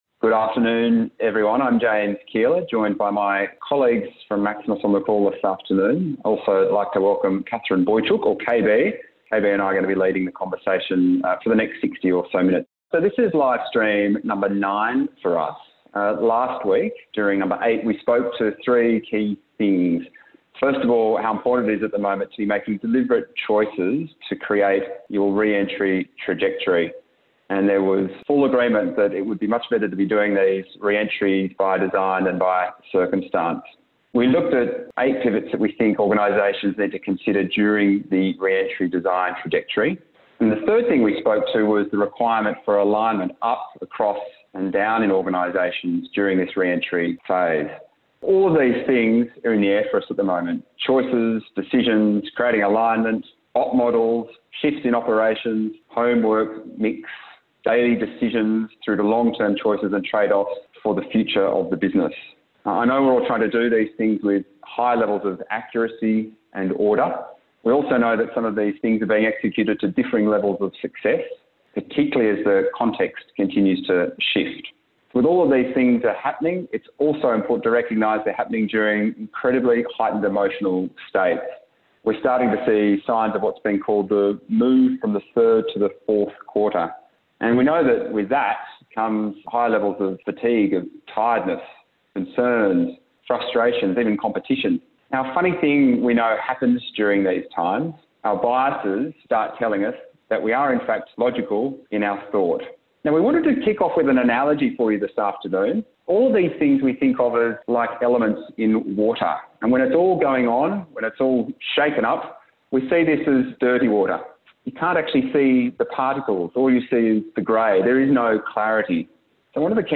Listen to an audio recording of our livestream, view the accompanying presentation: